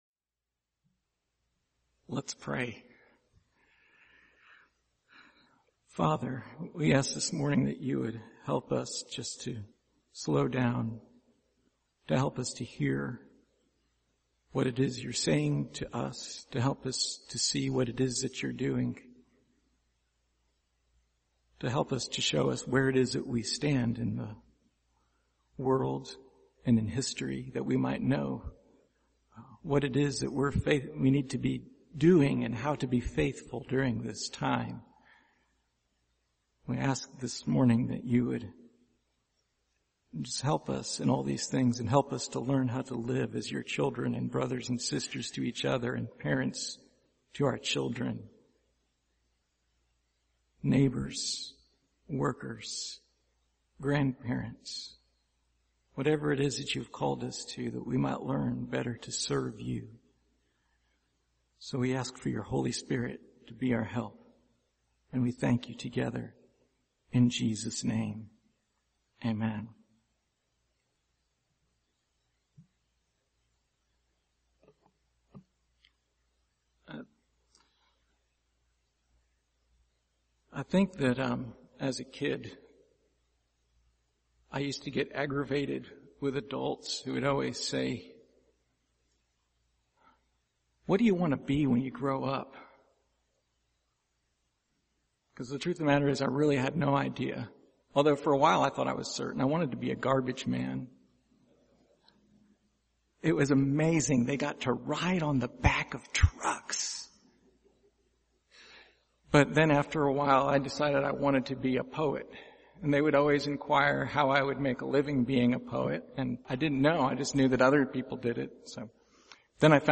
Passage: 1 Corinthians 15:36-49 Service Type: Sunday Morning